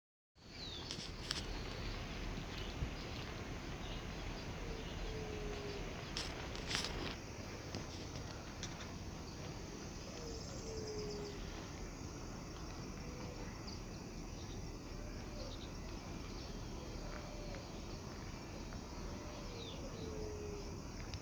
Yerutí Común (Leptotila verreauxi)
Nombre en inglés: White-tipped Dove
Fase de la vida: Adulto
Localidad o área protegida: Reserva Ecológica Costanera Sur (RECS)
Condición: Silvestre
Certeza: Vocalización Grabada